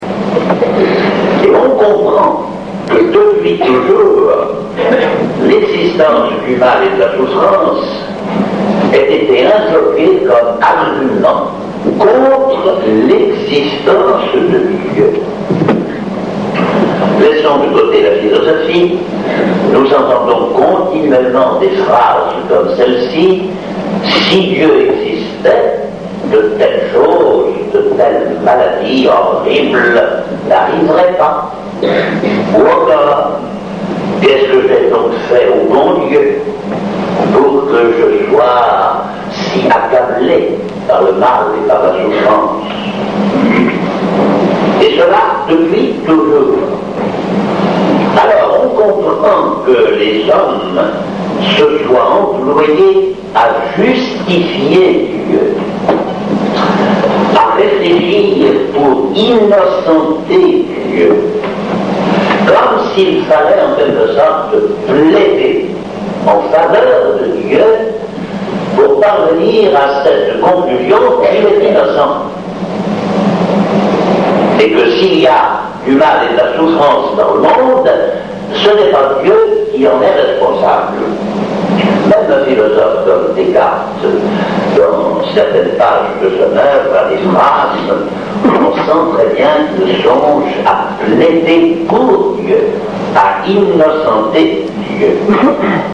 Je les enregistrais sur un « mini-cassette ». Ces merveilleux petits appareils qui avaient remplacé les gros magnétophones à bande n’offraient pas les possibilités de ceux que l’on a de nos jours. De plus j’effectuais ces enregistrements à partir de l’endroit où j’étais assis dans la salle. Mais la sonorisation n’était pas mauvaise.
Le son n’est pas très bon mais la diction du conférencier est si parfaite qu’on suit aisément ses explications.